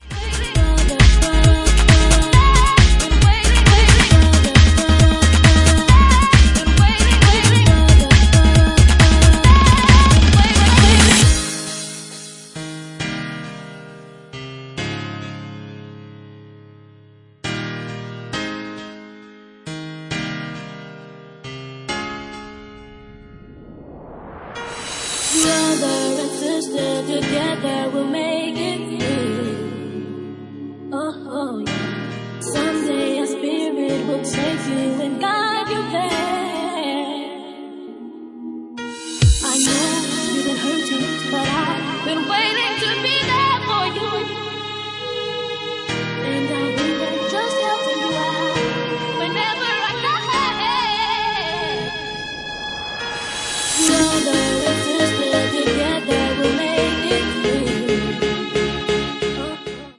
Genre:Bassline House
Bassline House at 135 bpm